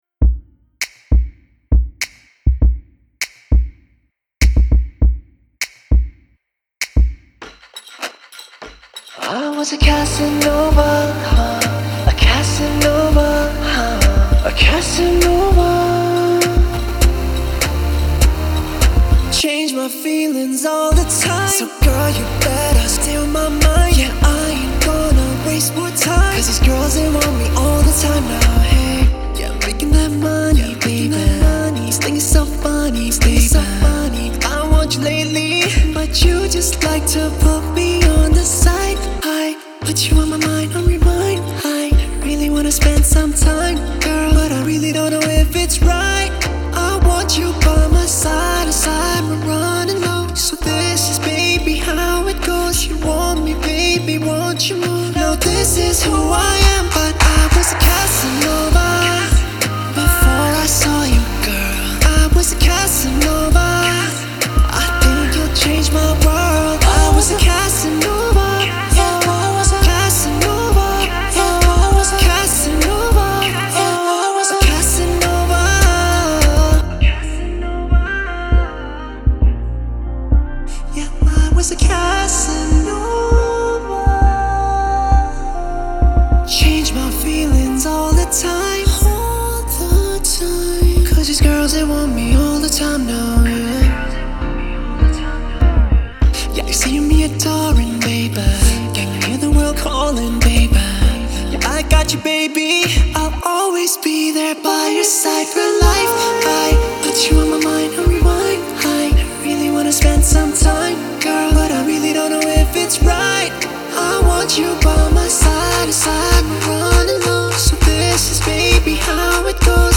это энергичная и мелодичная песня в жанре инди-поп